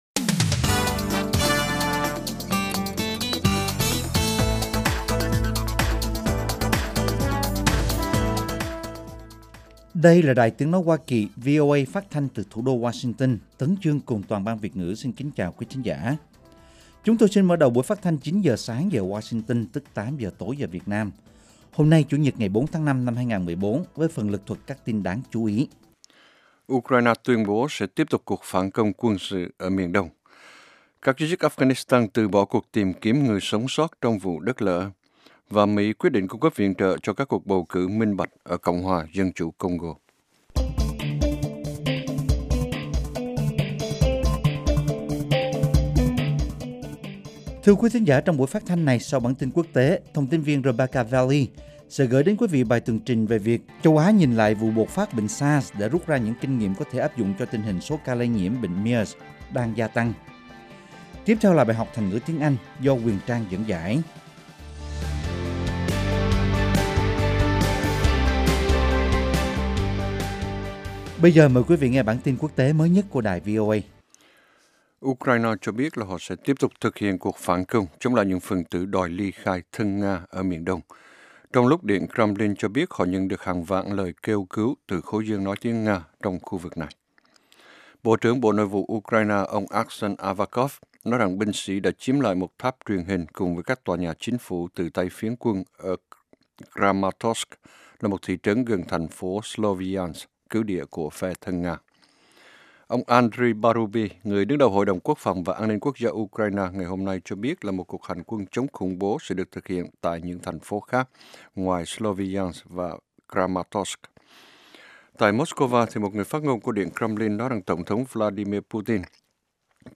Tin tức mới nhất và các chuyên mục đặc biệt về Việt Nam và Thế giới. Các bài phỏng vấn, tường trình của các phóng viên ban Việt ngữ về các vấn đề liên quan đến Việt Nam và quốc tế.